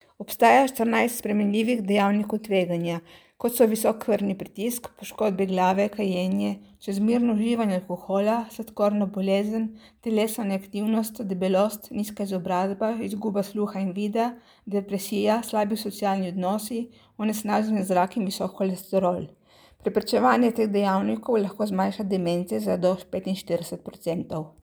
Tonske izjave: